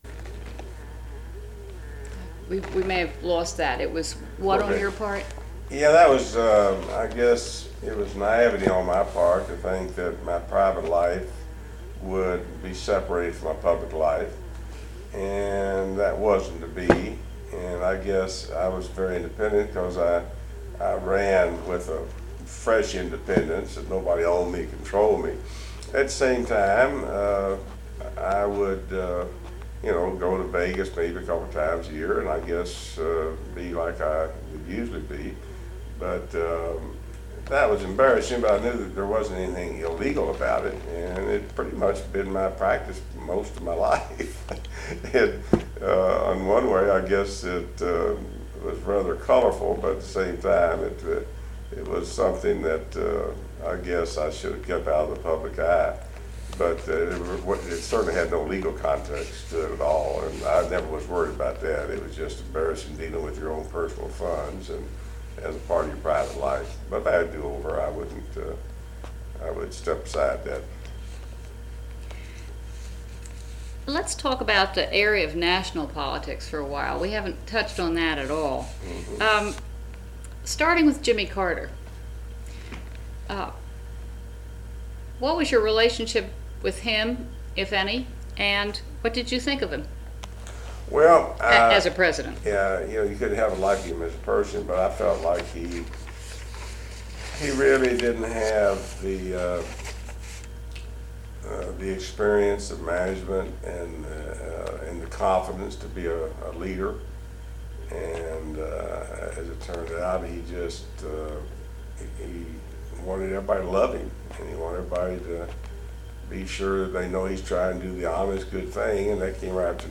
Oral History Interview with John Y. Brown, Jr., June 22, 1998